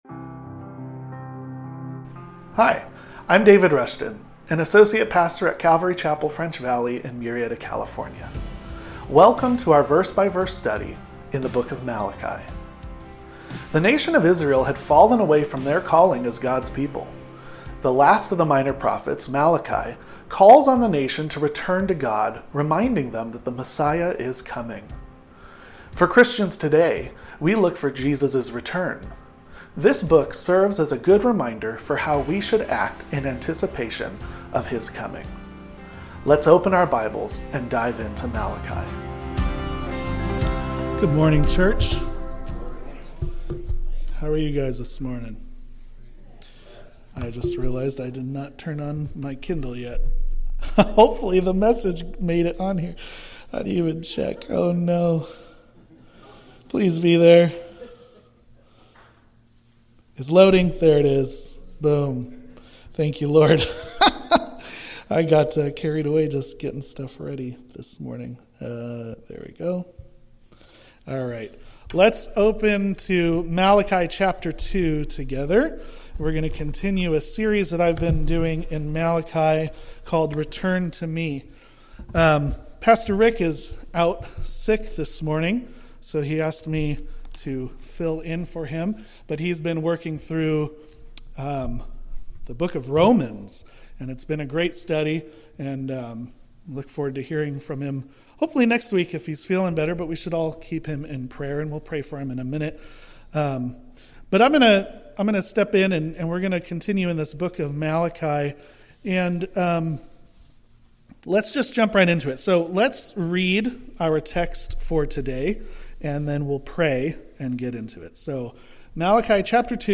The Sermons - Calvary Chapel French Valley